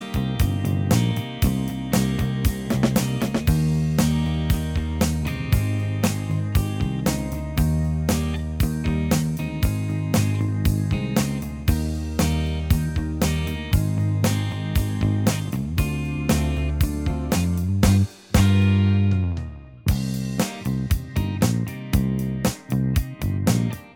Minus Lead And Solo Guitar Easy Listening 4:20 Buy £1.50